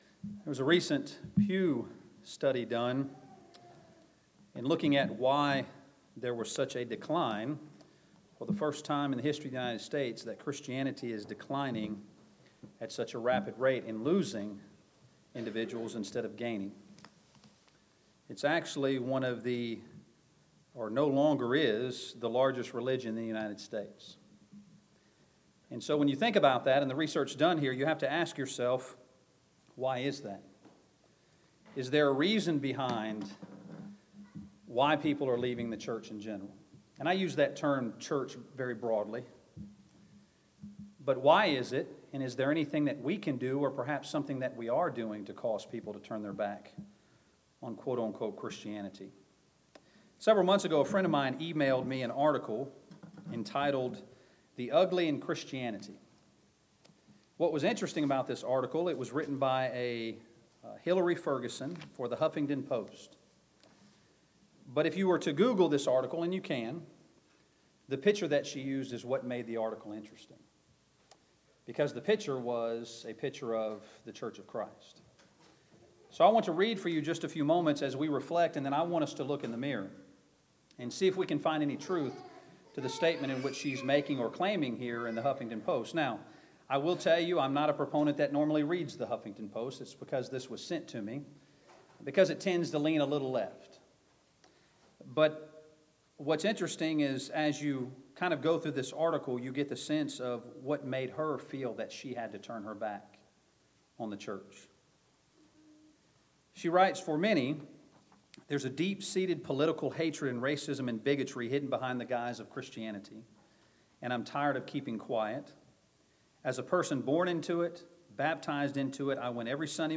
2019 Spring Gospel Meeting Service Type: Gospel Meeting Preacher